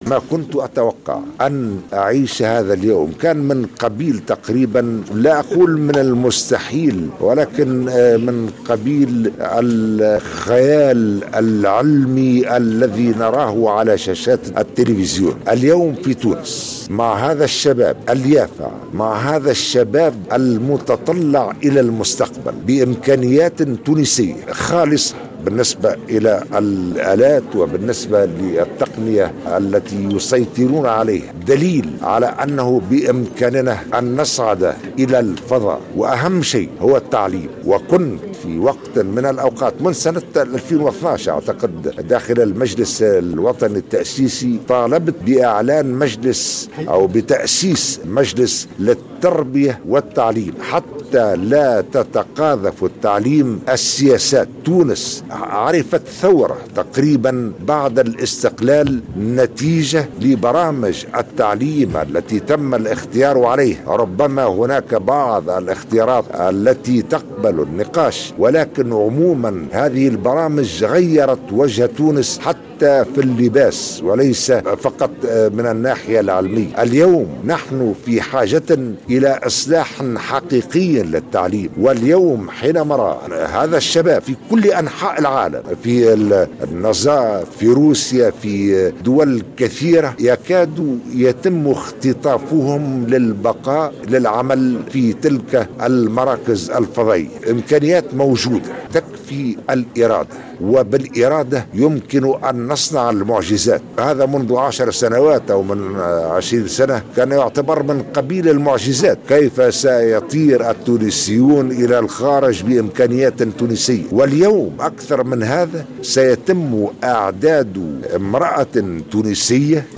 قال رئيس الجمهورية، قيس سعيد لدى حضوره، اليوم الاثنين، عملية إطلاق أول قمر صناعي تونسي، إنه بالإرادة يمكننا صُنع المعجزات على الرغم من قلة الإمكانيات.